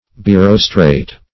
Search Result for " birostrate" : The Collaborative International Dictionary of English v.0.48: Birostrate \Bi*ros`trate\, Birostrated \Bi*ros"tra*ted\, a. [Pref. bi- + rostrate.] Having a double beak, or two processes resembling beaks.